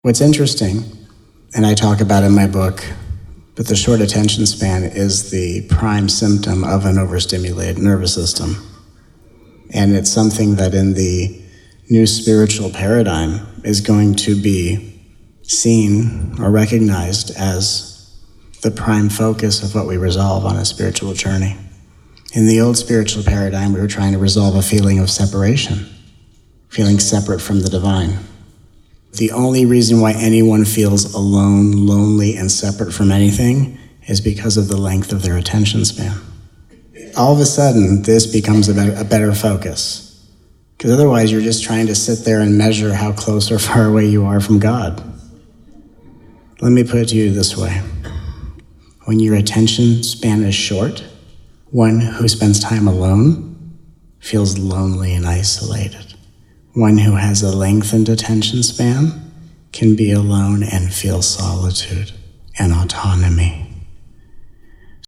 Please join us for these 14 hours of nourishing, uplifting, often hilarious, peaceful, and powerful teachings from this miraculous 5-day retreat.